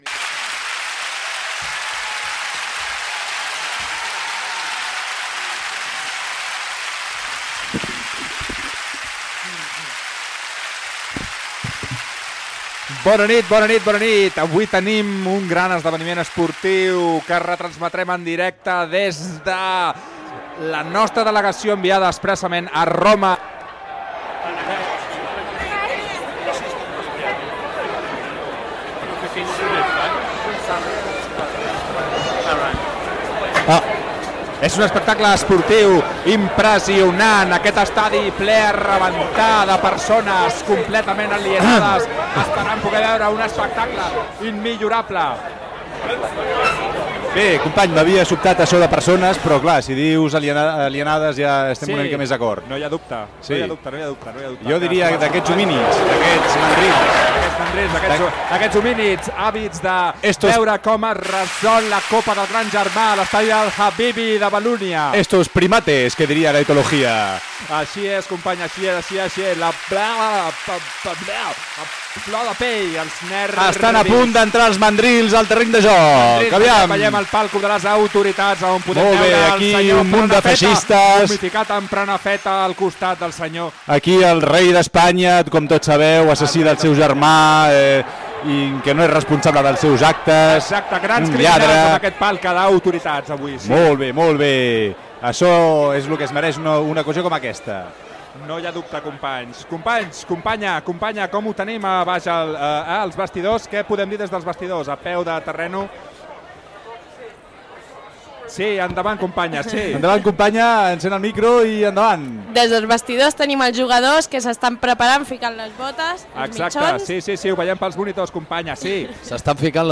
Transmissió ficcionada i crítica dels minuts previs a la final de la Lliga de Campions de la UEFA de 2009 que es va disputar a l'Estadi Olímpic de Roma. La final la van disputar el FC Barcelona i el Manchester United.
Ficció